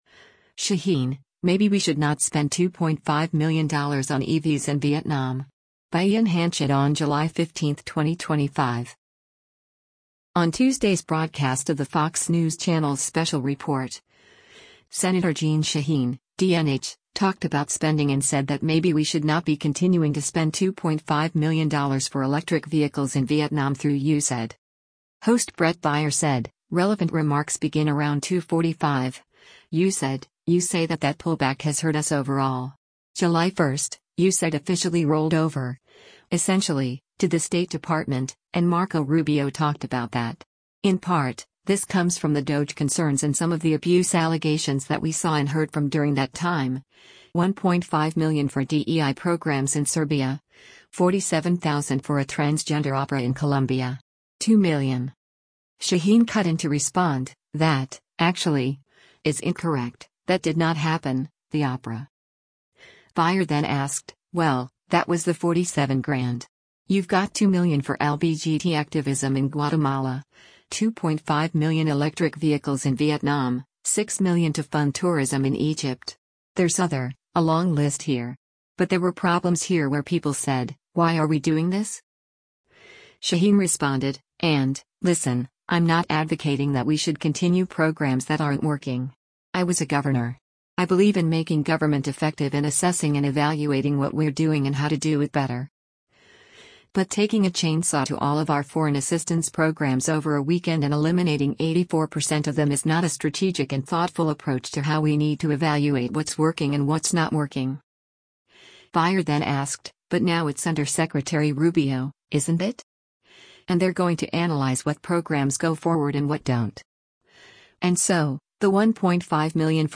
On Tuesday’s broadcast of the Fox News Channel’s “Special Report,” Sen. Jeanne Shaheen (D-NH) talked about spending and said that “maybe we should not be continuing” to spend $2.5 million for electric vehicles in Vietnam through USAID.